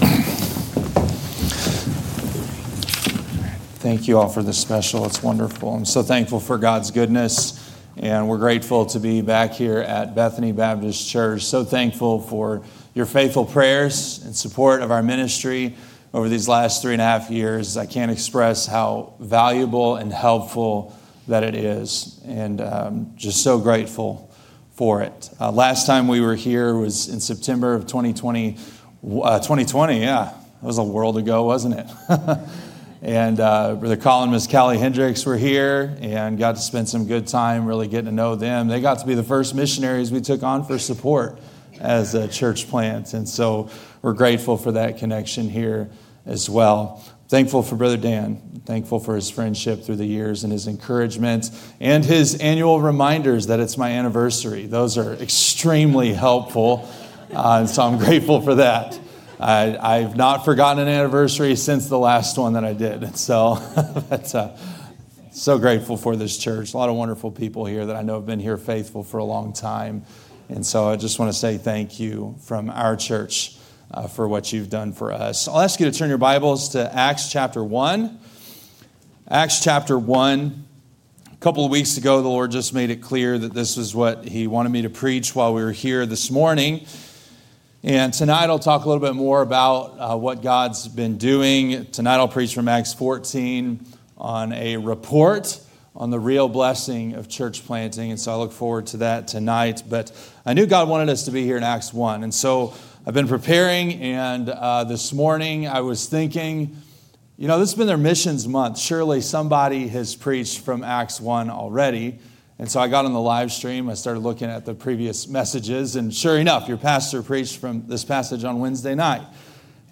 A message from the series "2025 Missions Month."